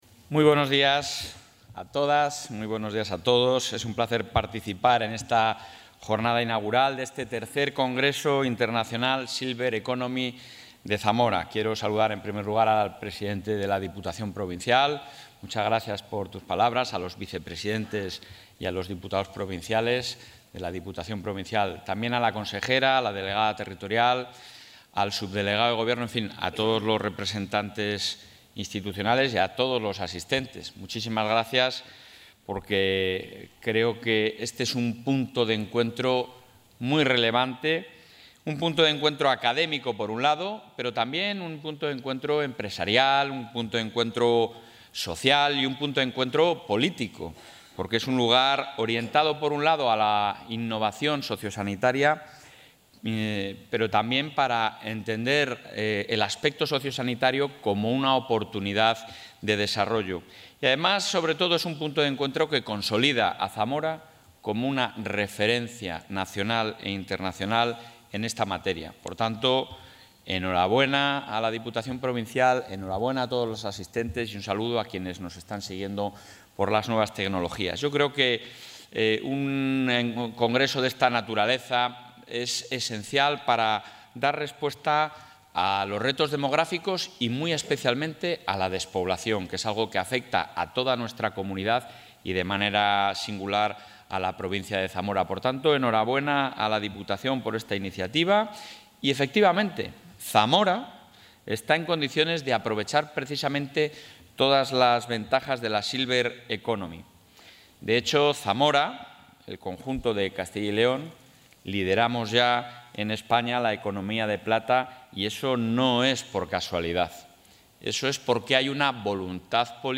Intervención del presidente de la Junta.
El presidente de la Junta de Castilla y León, Alfonso Fernández Mañueco, ha inaugurado esta mañana en Zamora el III Congreso Internacional Silver Economy, donde ha destacado la firme apuesta del Ejecutivo autonómico por que esta provincia se convierta en referente empresarial y tecnológico en materia sociosanitaria. Así, ha anunciado la creación de un «Hub» de empresas innovadoras con el que se potenciarán proyectos en este sector gracias a la colaboración público-privada.